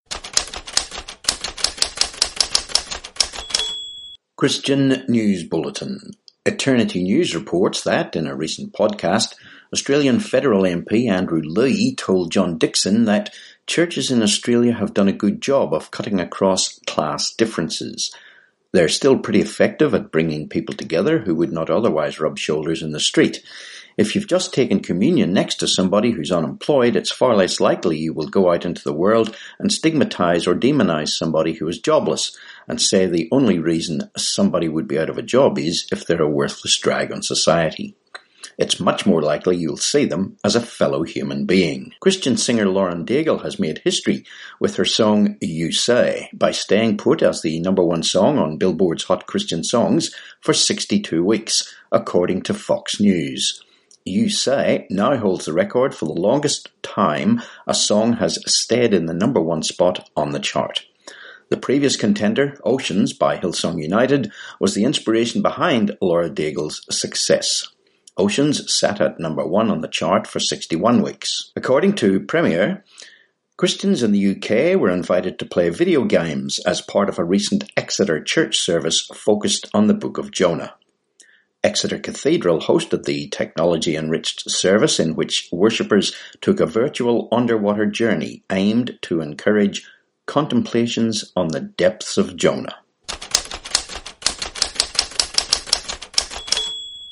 20Oct19 Christian News Bulletin